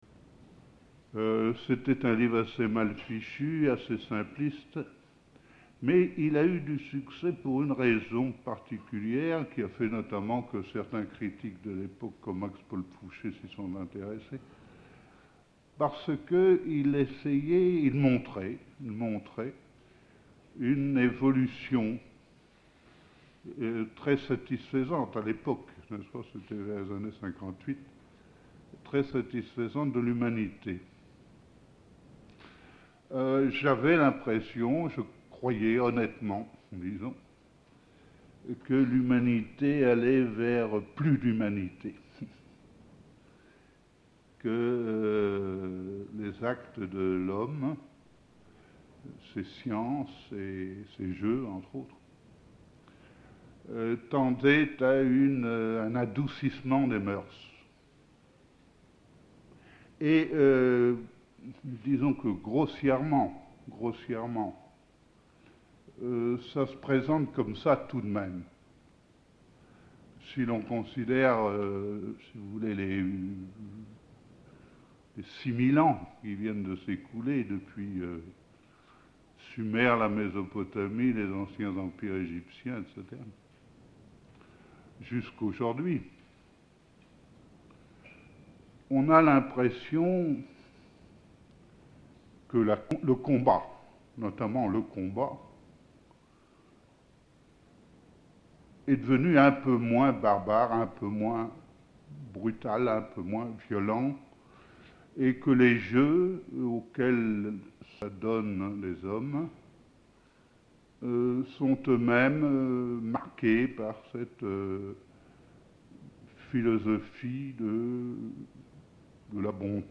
[Document audio : extrait d’une conférence à l’IUT de Nantes.